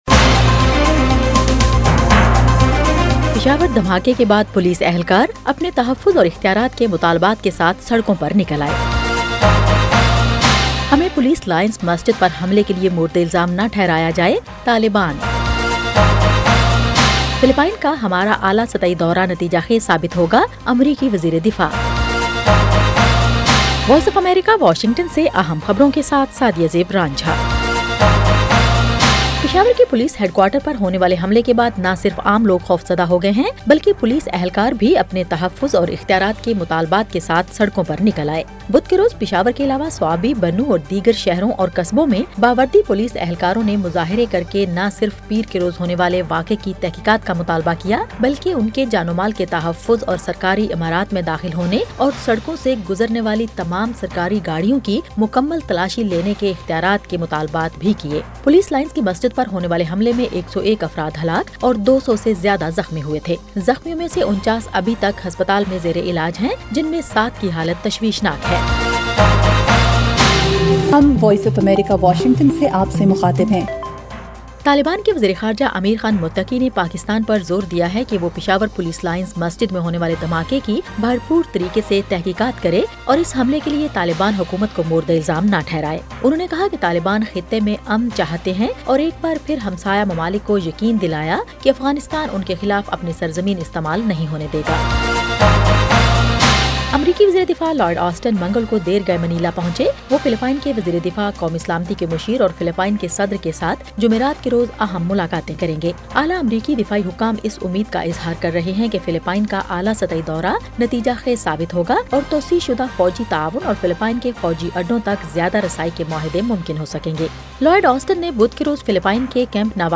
ایف ایم ریڈیو نیوز بلیٹن : رات 10 بجے